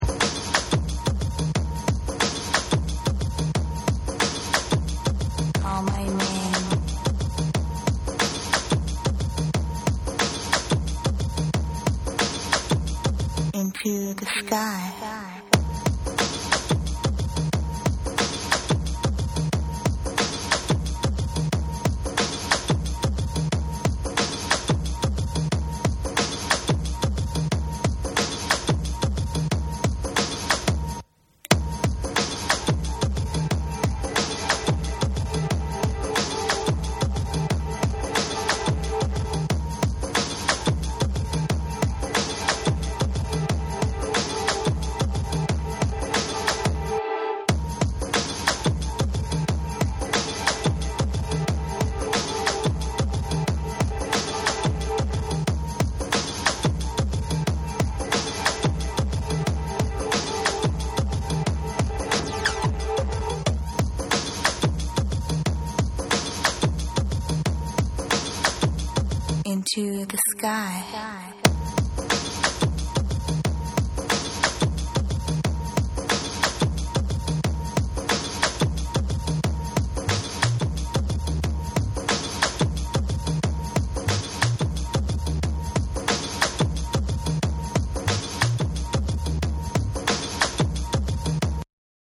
オーガニックでスピリチュアルなダブ・ブレイクビーツを披露したSAMPLE 2。
BREAKBEATS